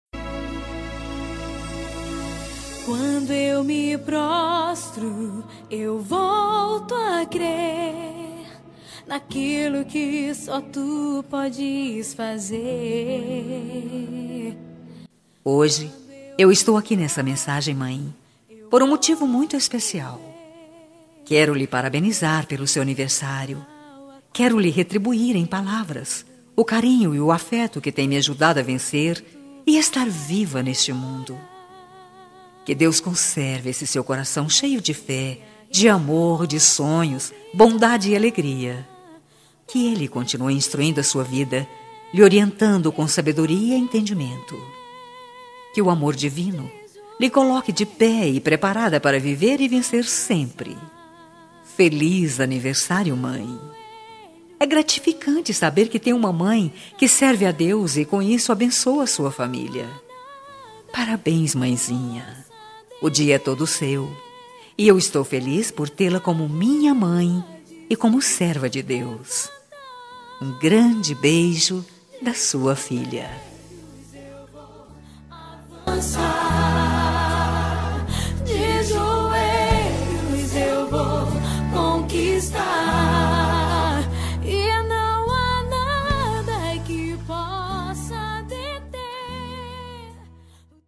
Telemensagem Evangélica Anversário Mãe | Com Reação e Recado Grátis